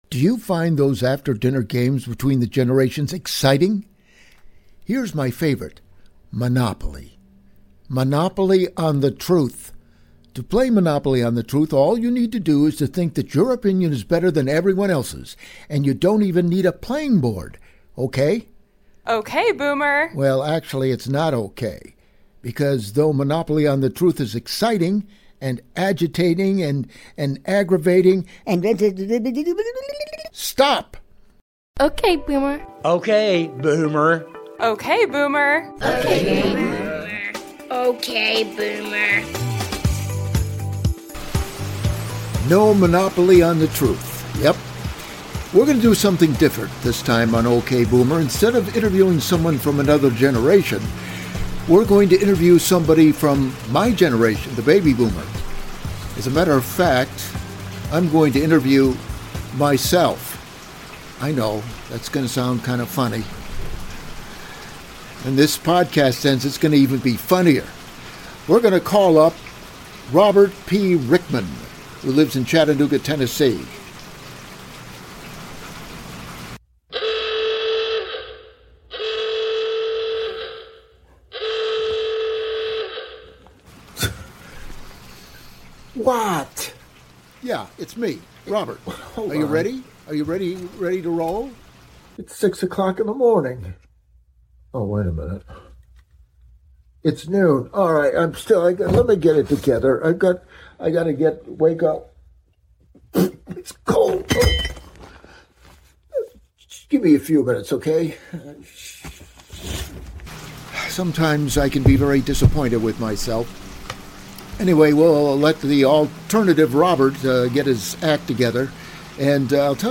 What happens when a Baby Boomer interviews… himself?
From tuition rants to coffee-fueled wisdom, this new OK Boomer episode has it all — including rain, laughter, and no monopoly on the truth.